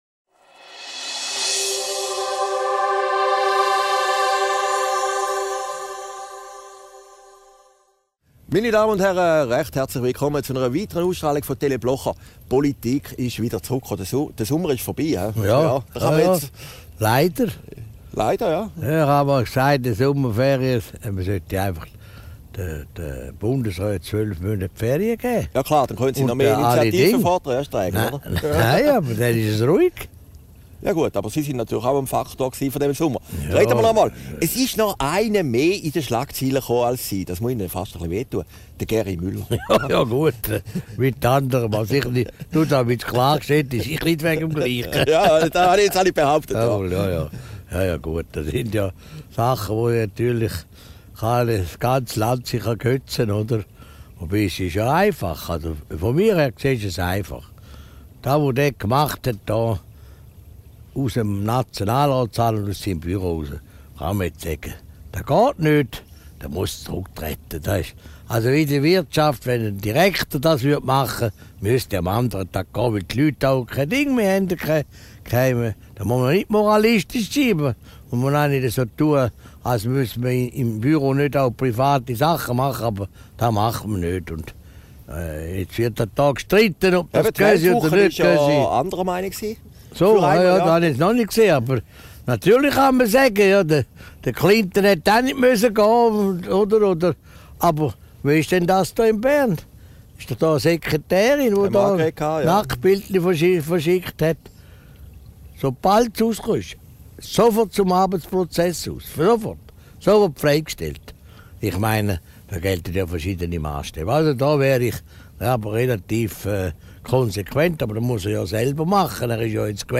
Video downloaden MP3 downloaden Christoph Blocher über Geri Müller, Adolf Ogi, abtrünnige SVP-ler und die Völkerrechtsdiskussion Aufgezeichnet in Herrliberg, am 22.